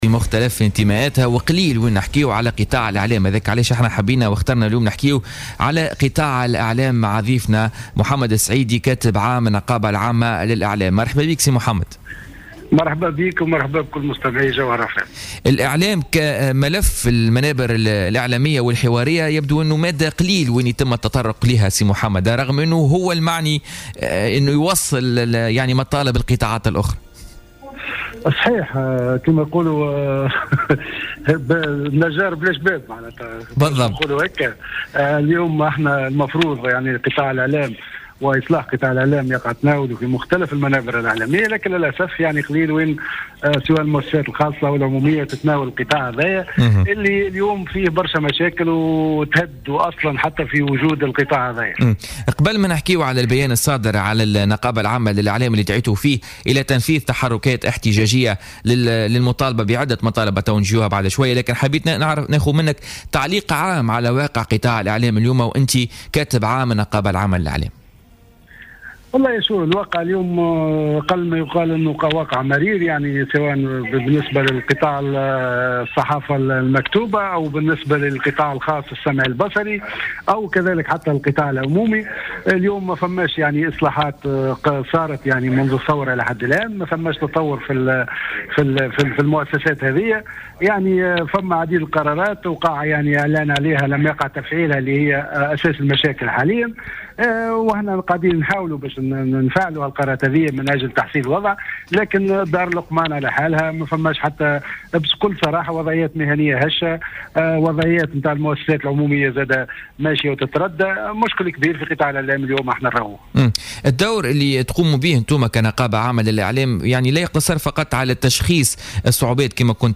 وقال في اتصال هاتفي بـ "الجوهرة أف أم" في برنامج "بوليتيكا" إن مداخيل الإشهار التي حققتها المؤسسة العمومية لم تتجاوز 400 ألف دينار مقابل نفقات بنحو 4.5 ملايين دينار بمناسبة شبكة رمضان 2017 ، في فشل غير مسبوق في تاريخ هذه المؤسسة العمومية، بحسب تعبيره.